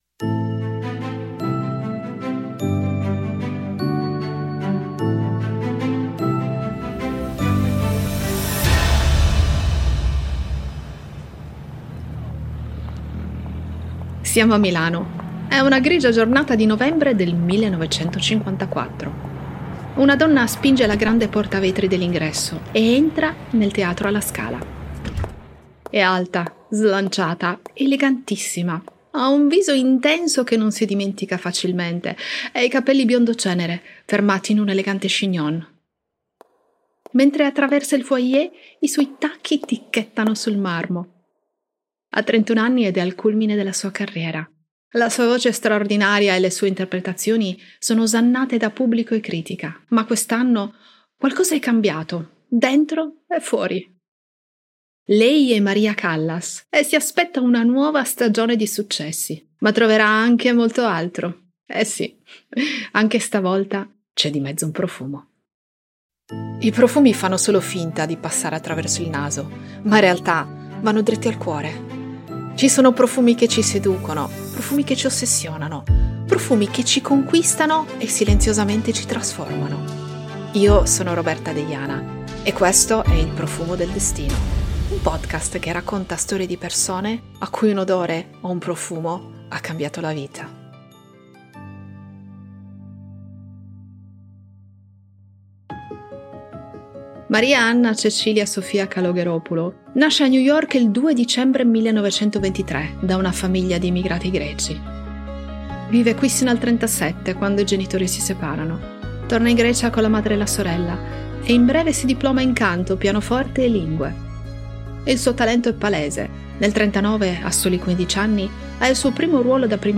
it Genres: Fiction Contact email